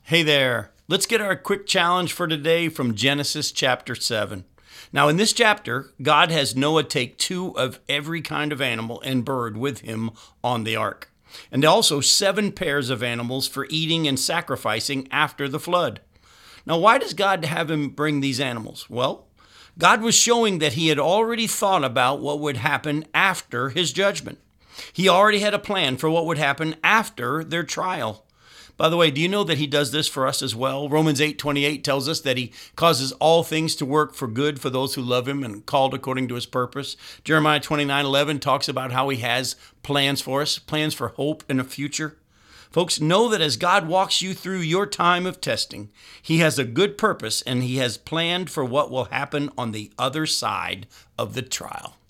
five-minute weekday radio program aired on WCIF 106.3 FM in Melbourne, Florida